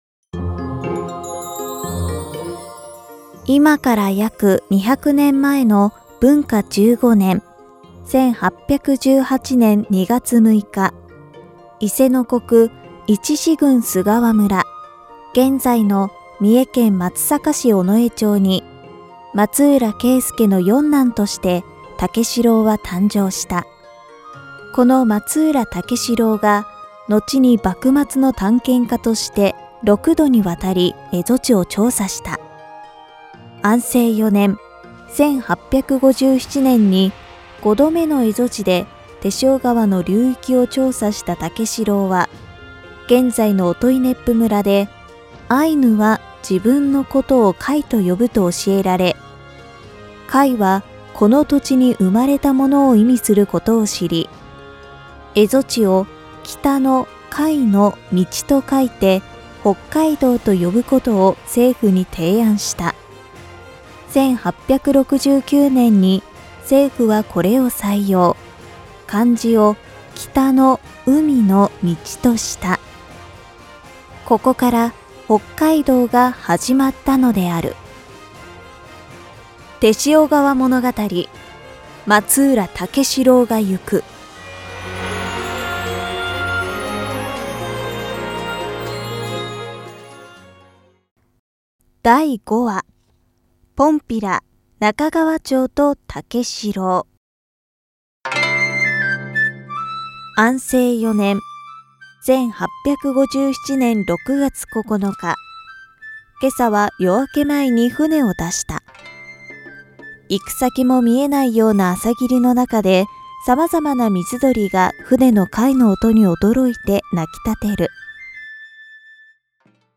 解説